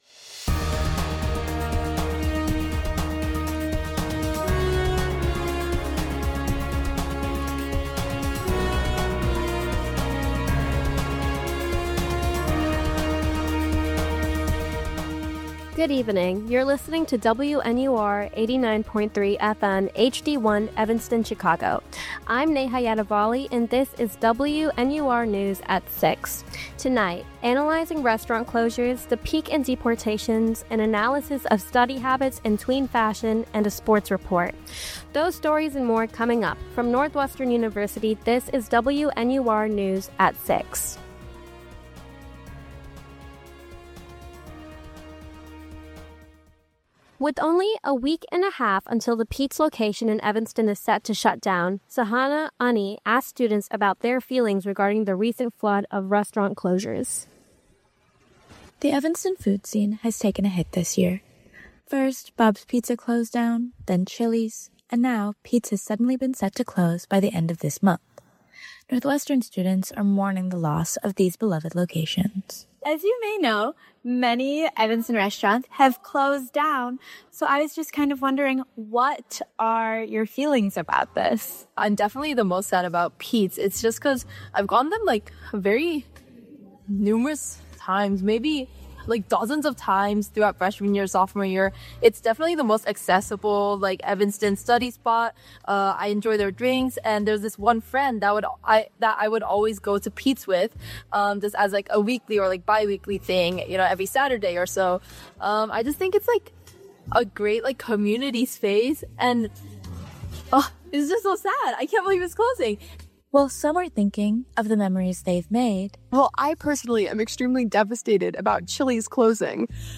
January 21, 2026: Analyzing restaurant closures, the peak in deportations, an analysis of study habits and tween fashion, and a sports report. WNUR News broadcasts live at 6 pm CST on Mondays, Wednesdays, and Fridays on WNUR 89.3 FM.